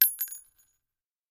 rifle_generic_4.ogg